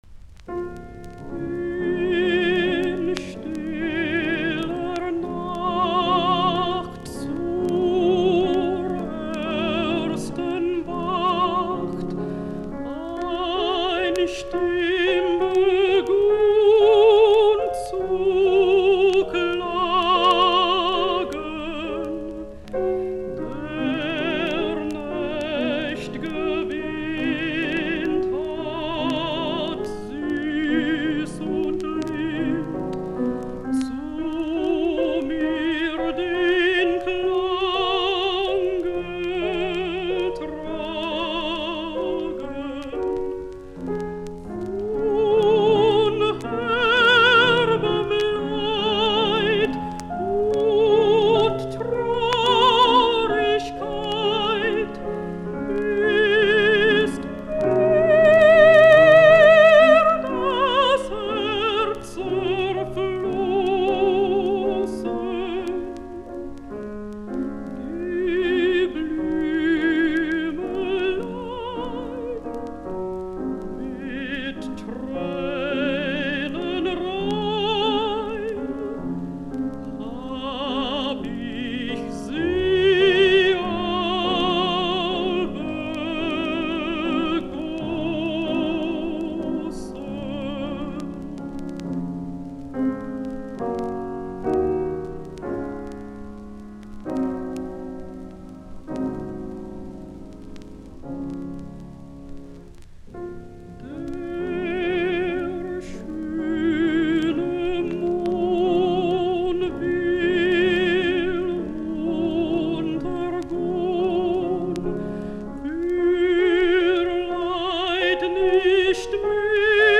musiikkiäänite
altto